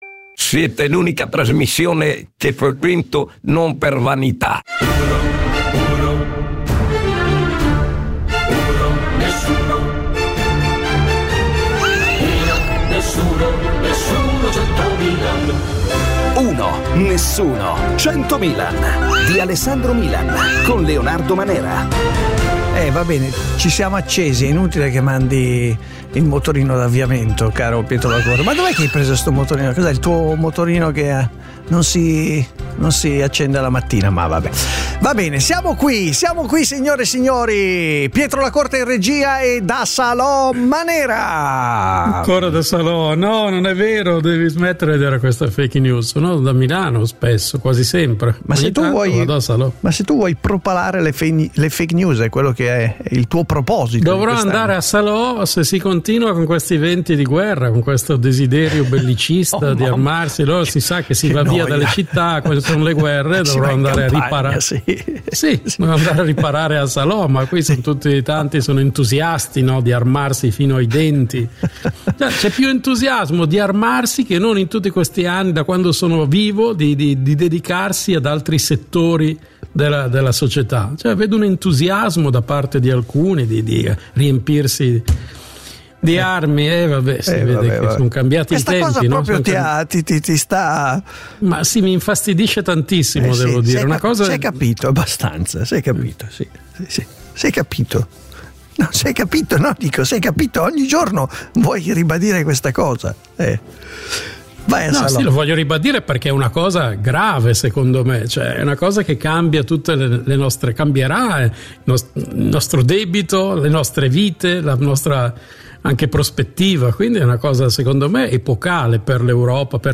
Un morning show sui fatti e i temi dell’attualità